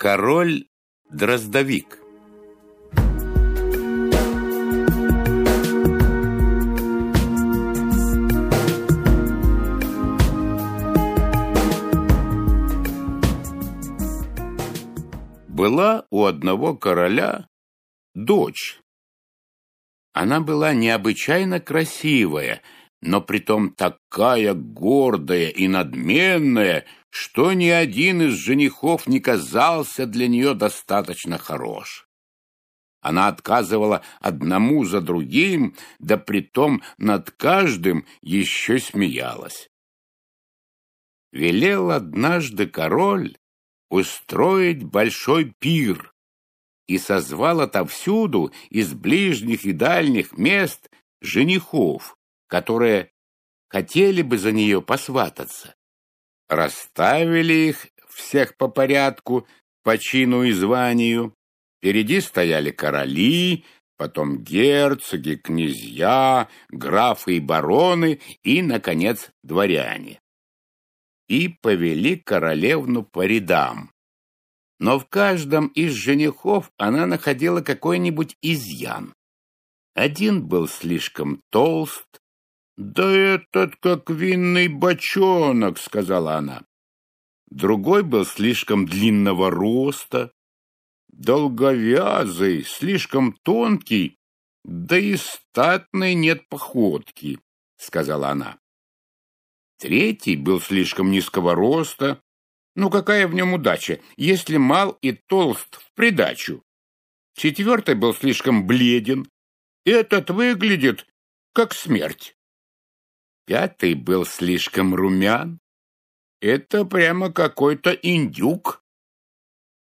Аудиокнига Королевские сказки | Библиотека аудиокниг